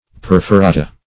Perforata \Per`fo*ra"ta\ (p[~e]r`f[-o]*r[=a]"t[.a]), n. pl. [NL.